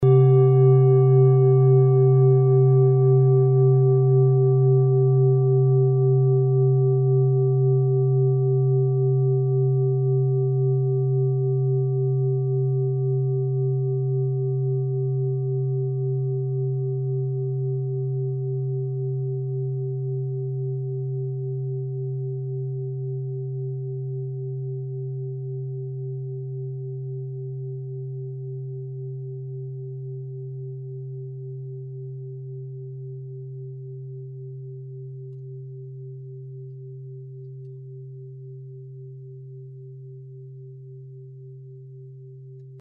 Tibet Klangschale Nr.44
Sie ist neu und wurde gezielt nach altem 7-Metalle-Rezept in Handarbeit gezogen und gehämmert.
Hörprobe der Klangschale
(Ermittelt mit dem Filzklöppel oder Gummikernschlegel)
klangschale-tibet-44.mp3